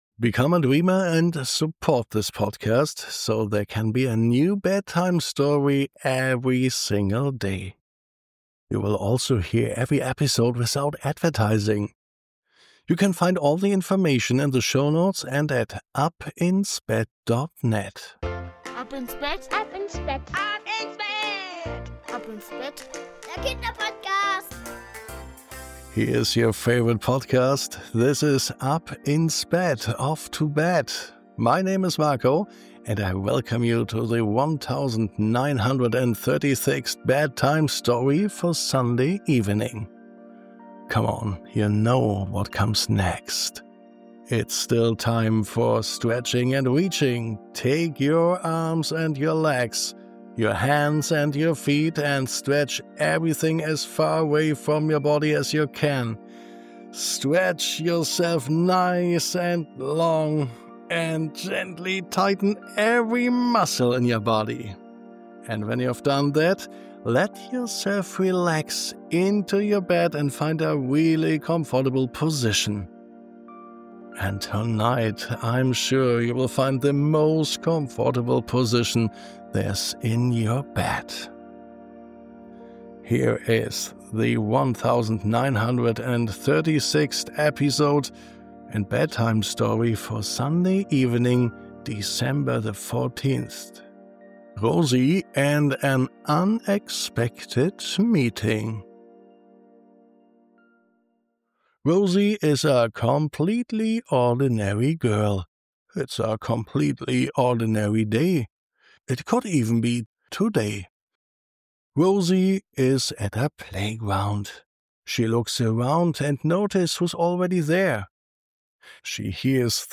A gentle bedtime story about unexpected meetings, quiet connection, and the beautiful feeling of feeling safe.